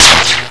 elecspark1.wav